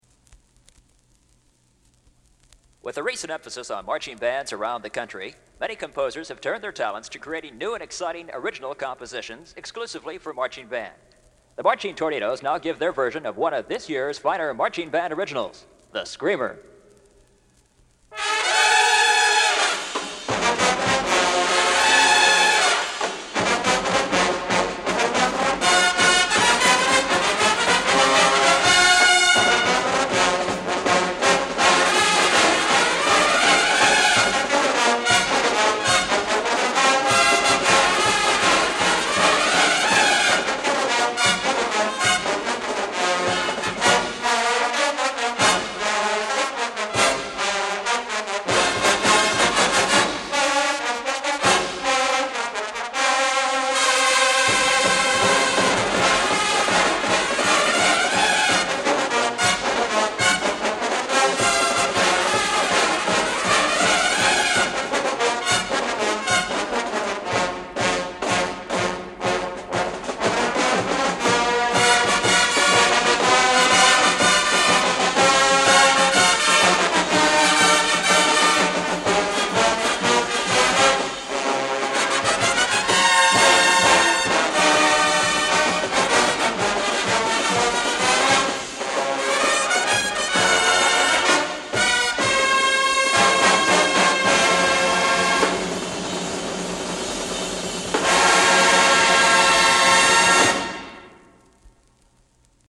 Marching Tornados Band
1973 Marching Tornados Band LP Recording